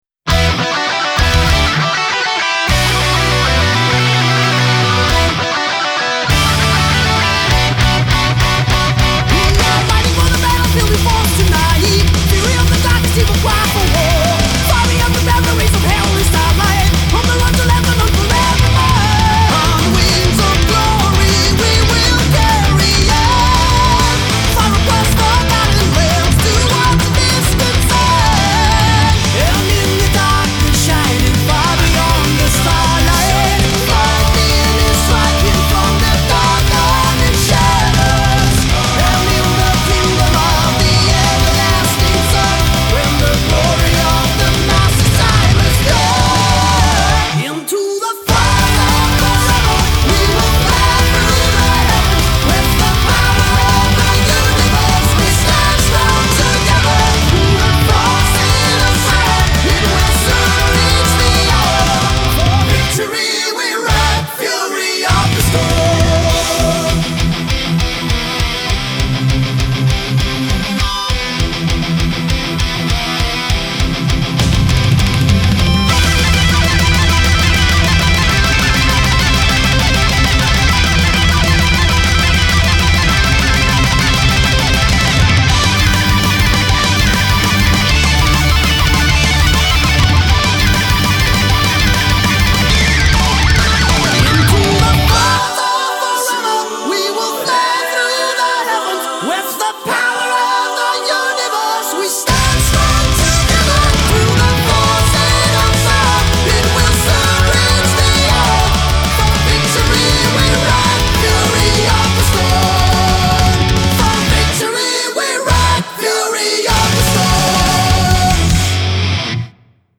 BPM200
Audio QualityPerfect (High Quality)
Comments[EXTREME POWER METAL]